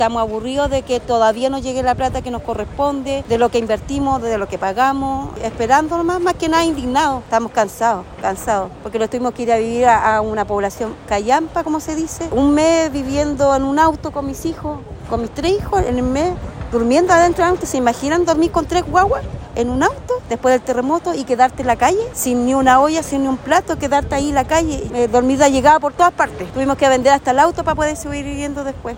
Una de las dueñas de los departamentos que serán parte de la demolición del edificio Puerto de Palos, expresó su molestia y frustración al ver los vestigios de su hogar.